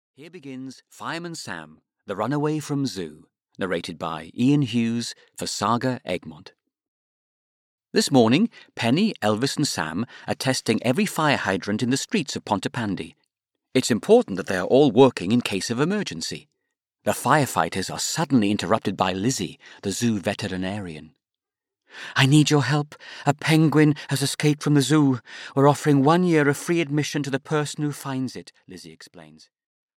Audio knihaFireman Sam - The Runaway from Zoo (EN)
Ukázka z knihy